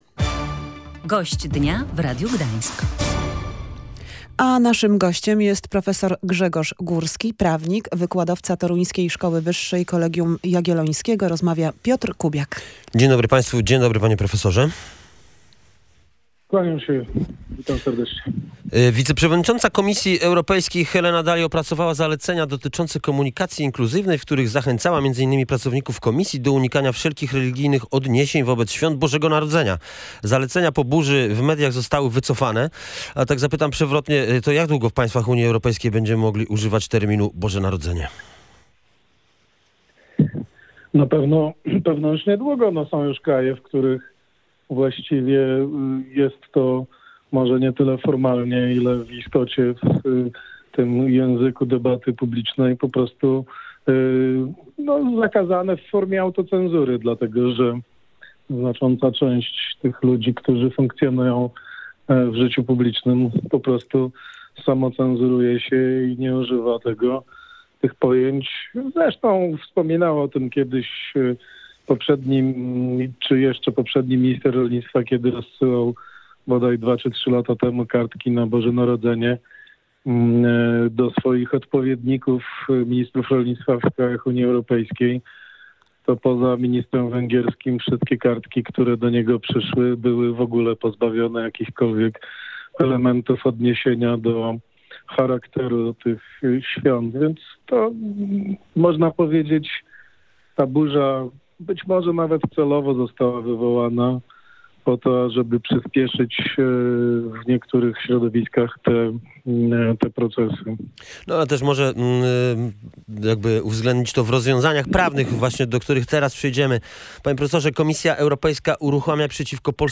– To jest możliwe – mówił prawnik w Radiu Gdańsk.